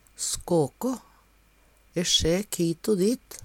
skåkå - Numedalsmål (en-US)